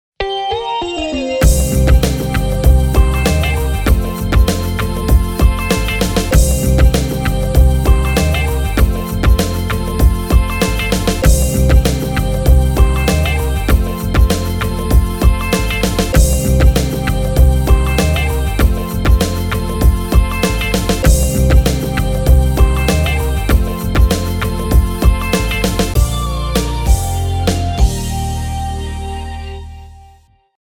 -Tempo Cool